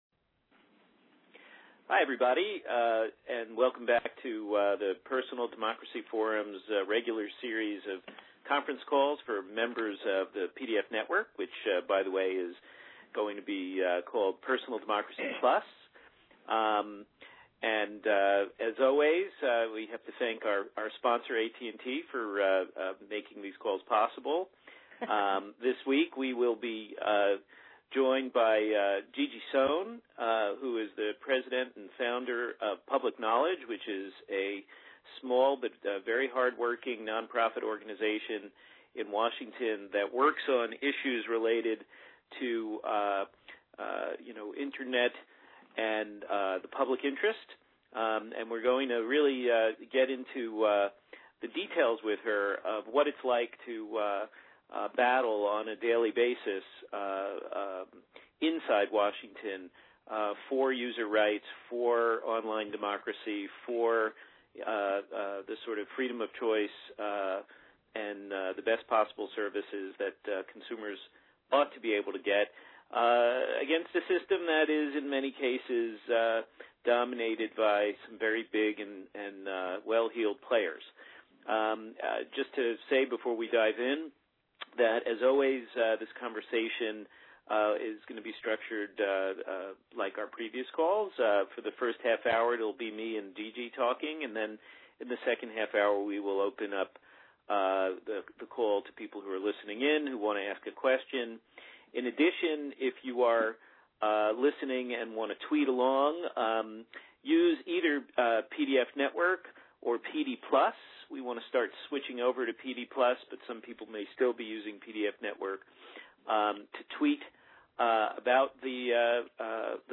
In this talk, we get her inside perspective on what it’s like to try to get those policies enacted (or overturned, depending).